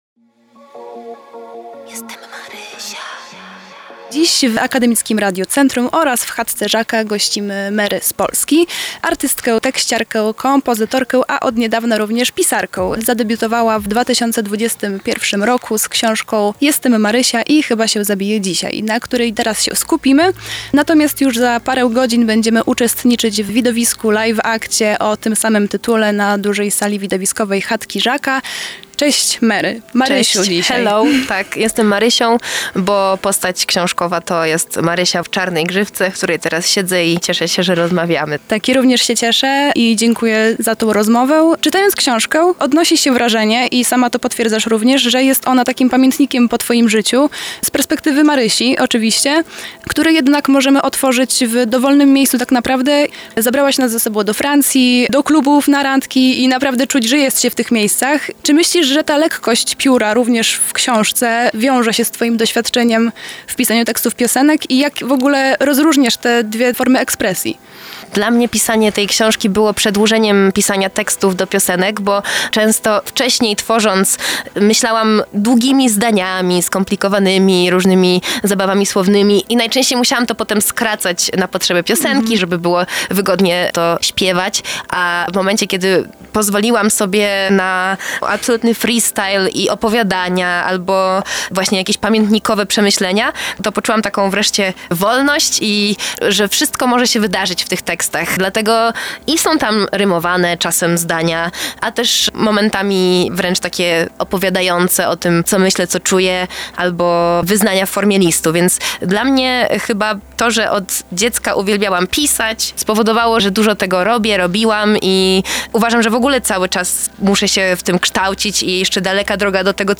mery-wywiad1.mp3